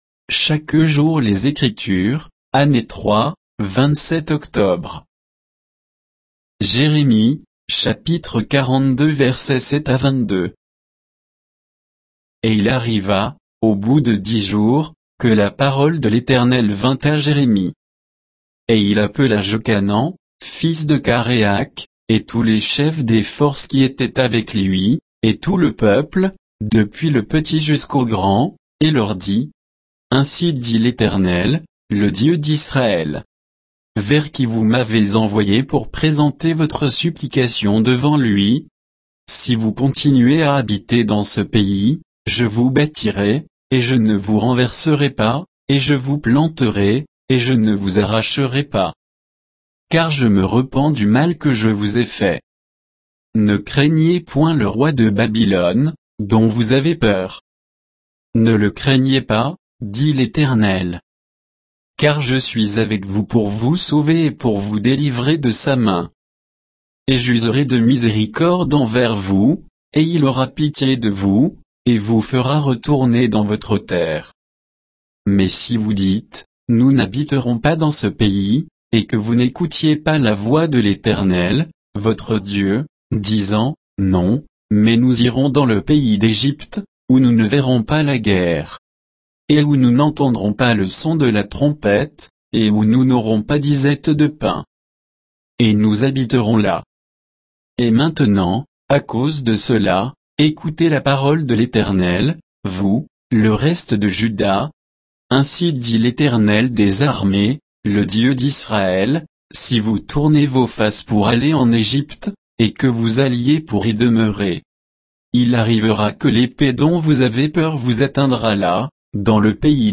Méditation quoditienne de Chaque jour les Écritures sur Jérémie 42, 7 à 22